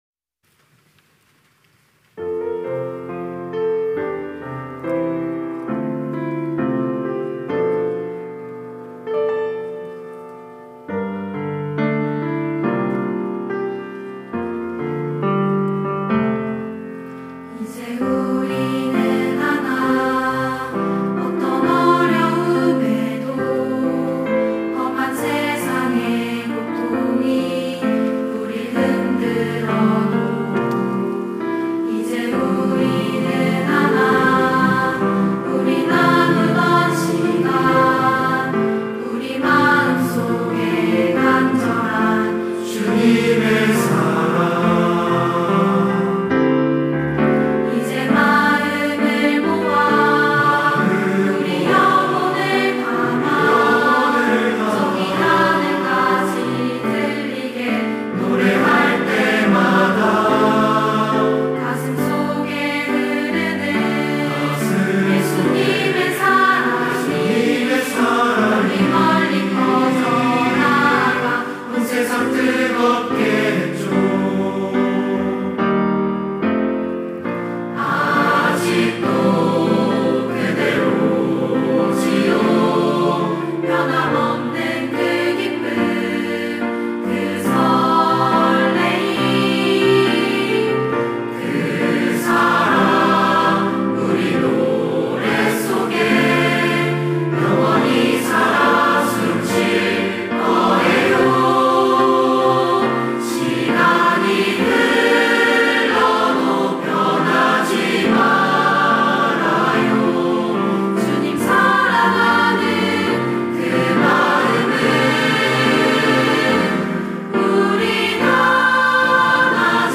특송과 특주 - 에클레시아여 영원히
이름 청년부 양육 3팀